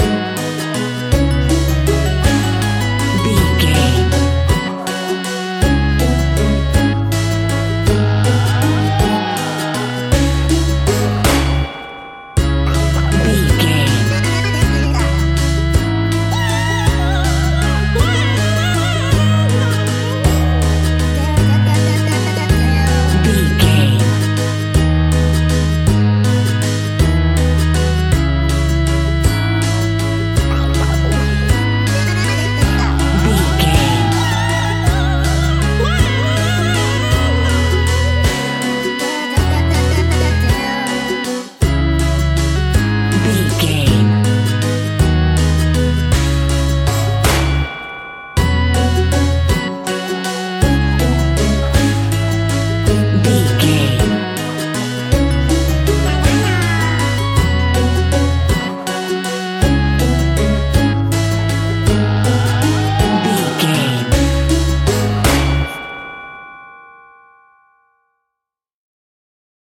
Aeolian/Minor
C#
ominous
suspense
eerie
acoustic guitar
percussion
strings
creepy
spooky